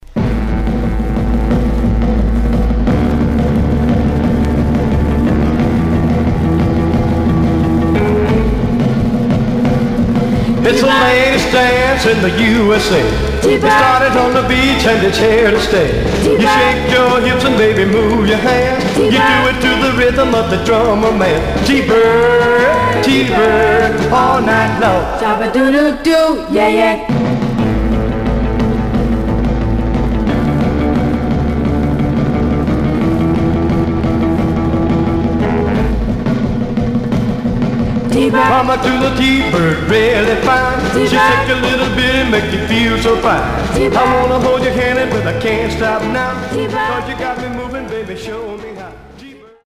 Stereo/mono Mono
Rockabilly Condition